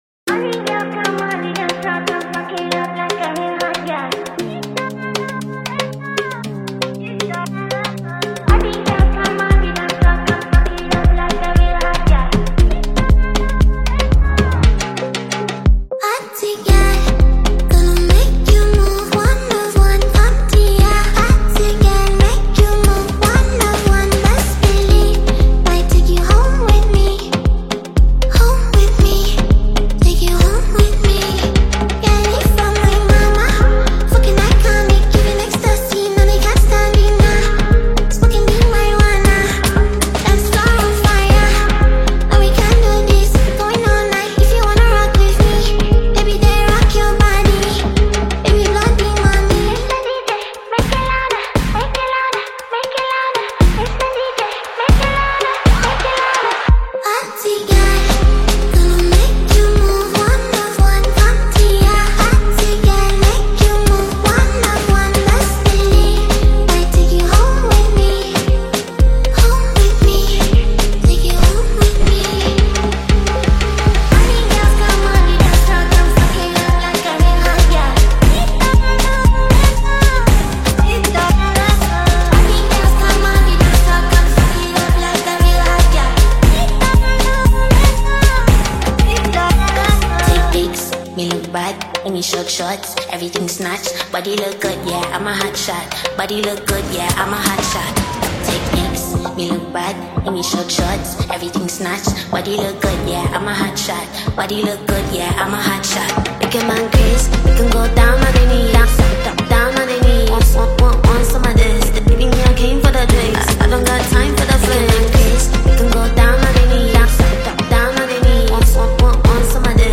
Ghana Music
Club-Ready Afro-Pop Anthem
distinctively airy yet powerful vocals